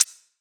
Perc Funk 7.wav